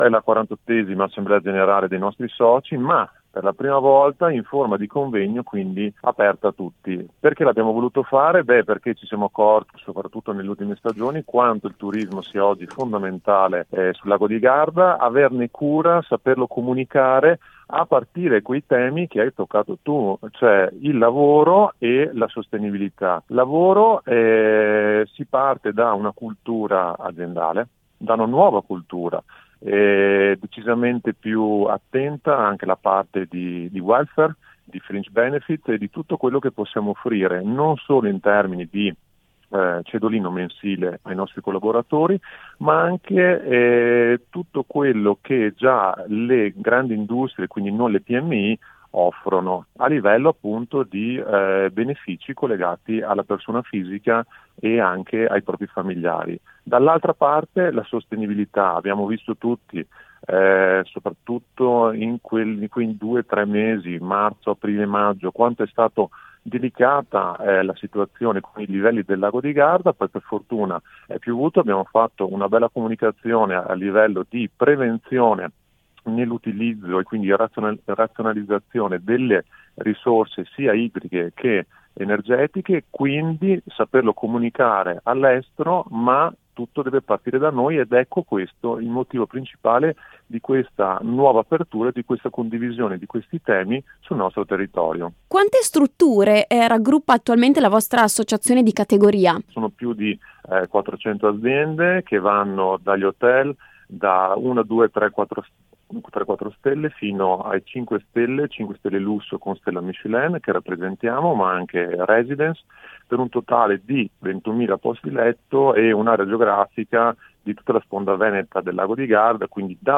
intervista-federalberghi-definitiva.mp3